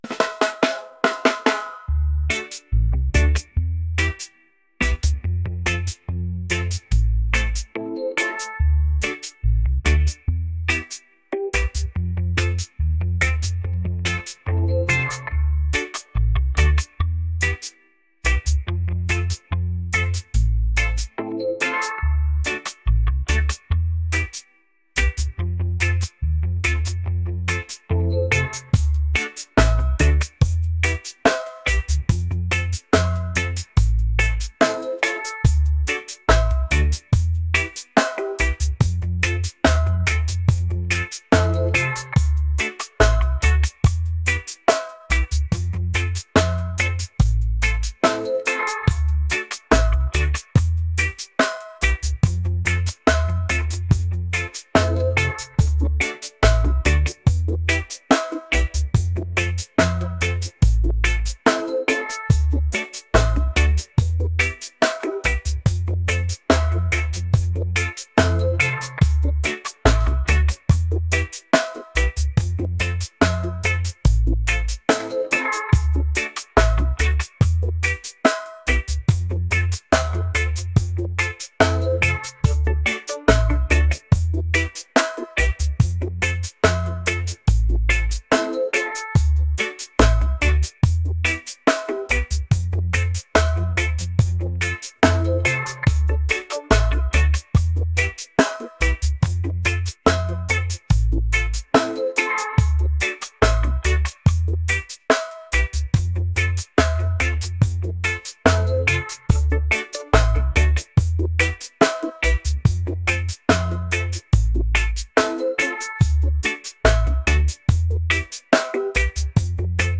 groovy | reggae